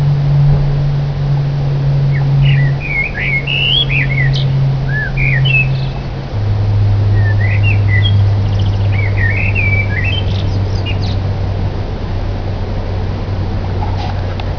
Abb. 02: Vogelstimmen über den Dächern einer Stadt.